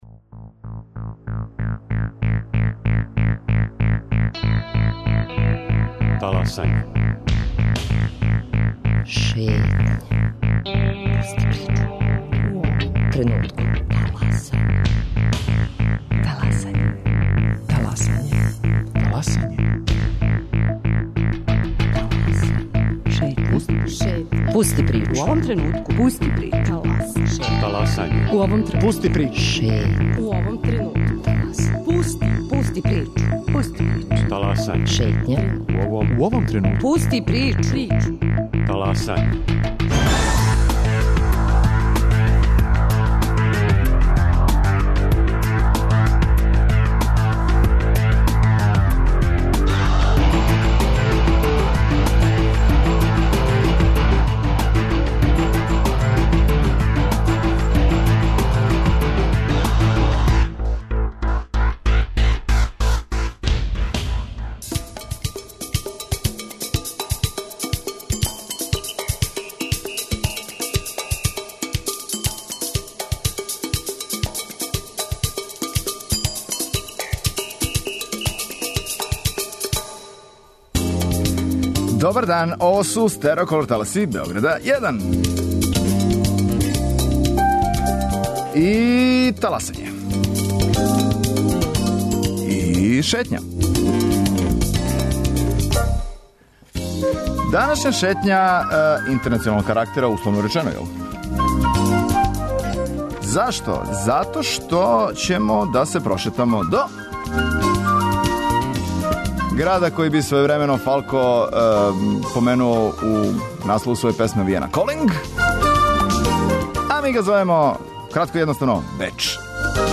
Шетња Радио Београда ексклузивно из студија Радија Аустрије у Бечу.